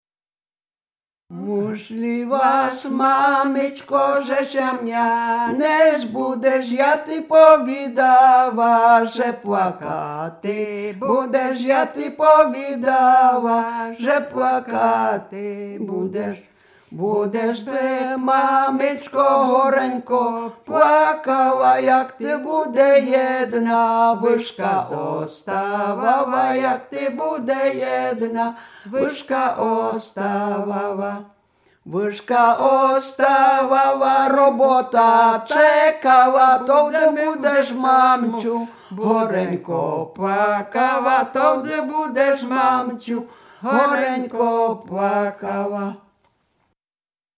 Śpiewaczki z Sobina
Łemkowszczyzna
Dolny Śląsk, gmina Polkowice, wieś Sobin
Weselna
Array weselne wesele oczepinowe przyśpiewki migracje